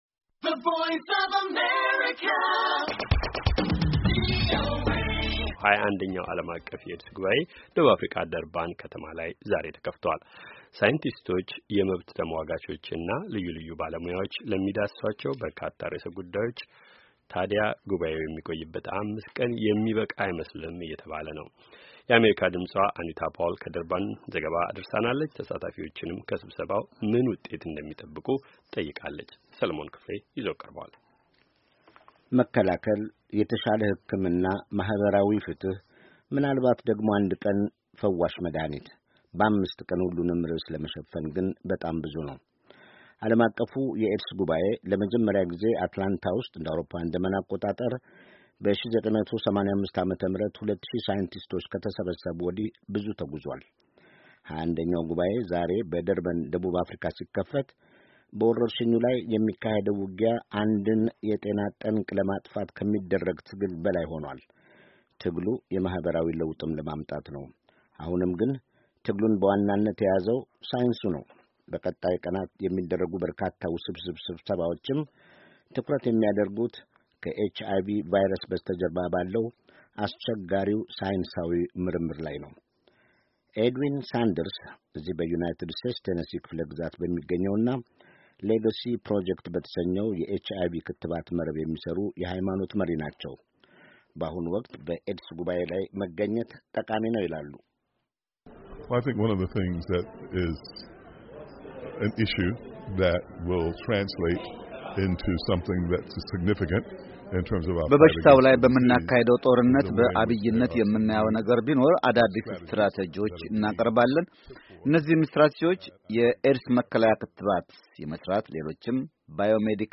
የአሜሪካ ድምፅ ተሳታፊዎችንም ከስበሰባው ምን ውጤት እንደሚጠብቁ ጠይቆ የተዘጋጀው ዘገባ ከተያያዘው የድምፅ ፋይል ያድምጡ።